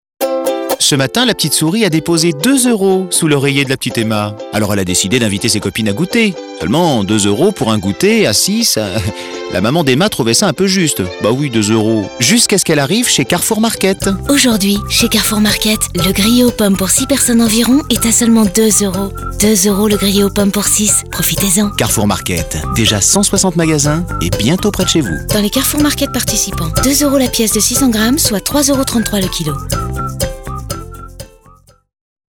Défi relevé avec cette campagne radio écrite pour Carrefour Market, avec la voix d’Emmanuel Curtil (la voix française de Jim Carrey) et des portraits de clients dans lesquels on sent toute la bienveillance de l’enseigne et son engagement à leur rendre service.